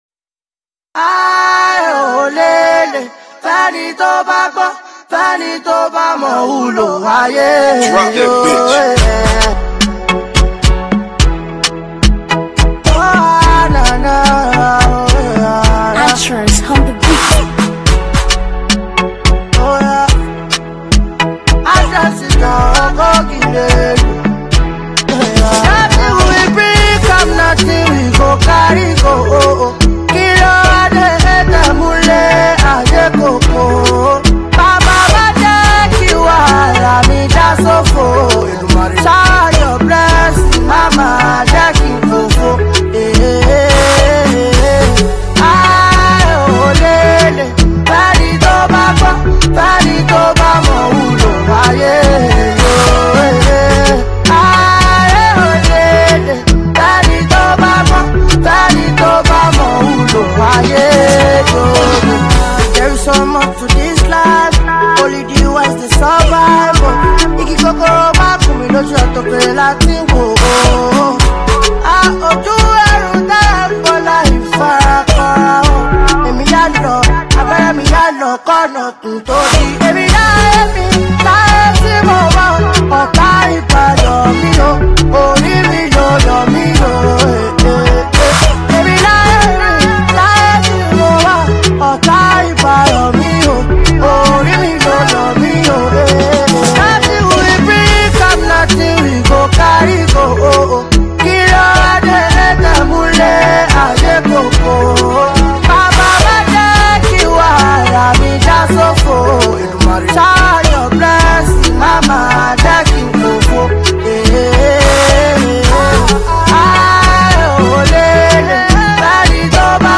motivational song
mid-tempo beat
beautiful welcoming vocals